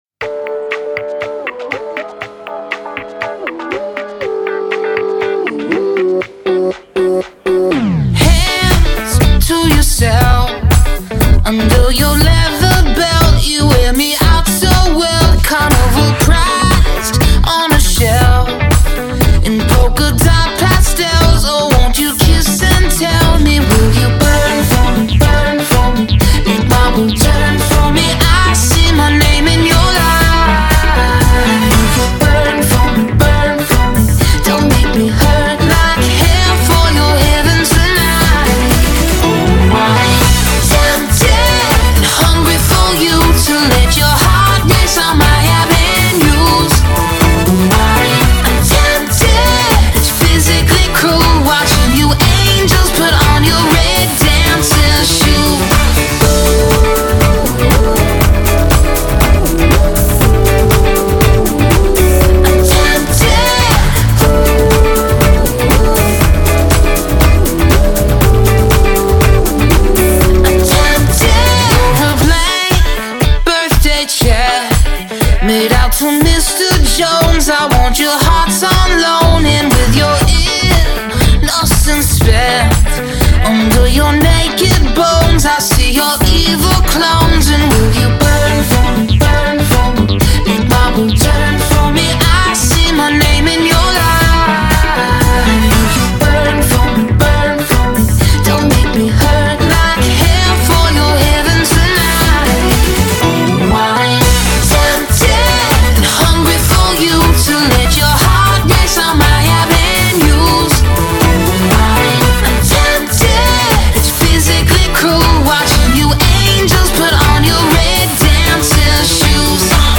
Жанр: Electronic